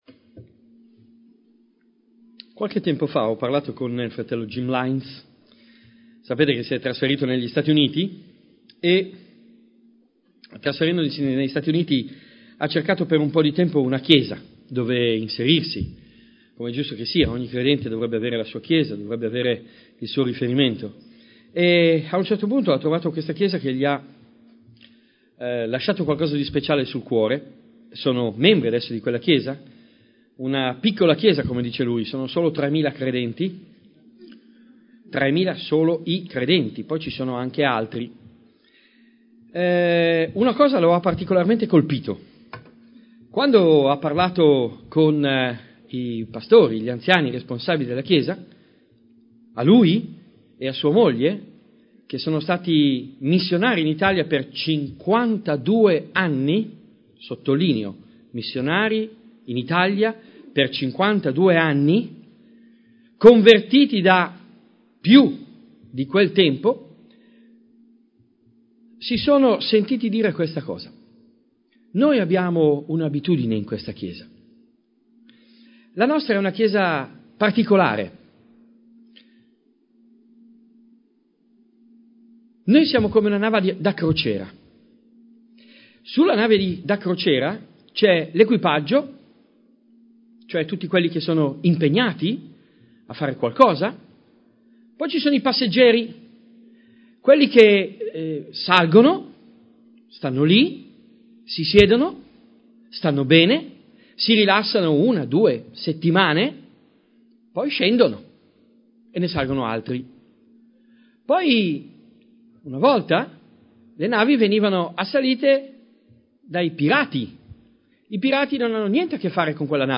Serie studi